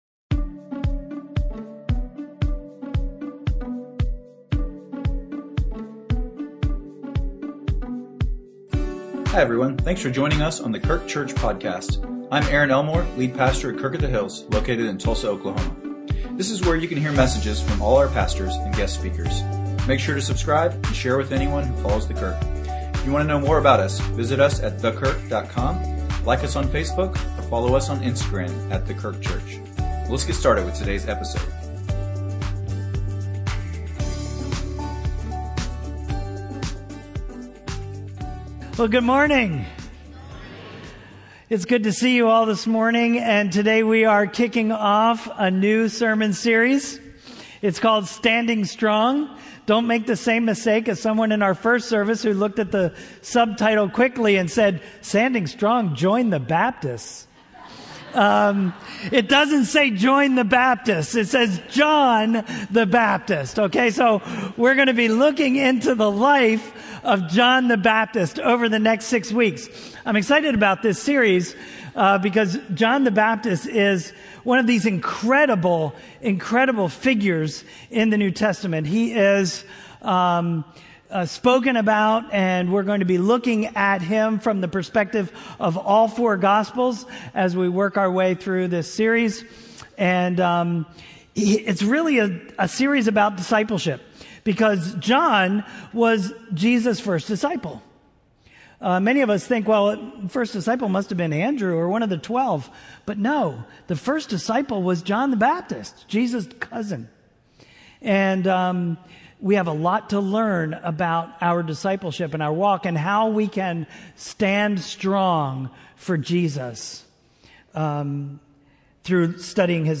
A message from the series "Standing Strong."